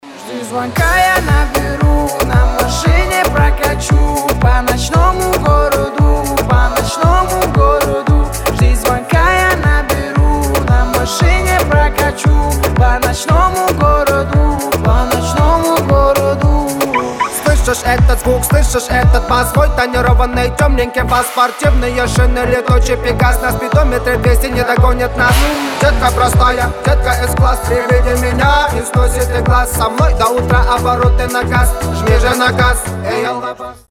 • Качество: 320, Stereo
поп
Хип-хоп
казахские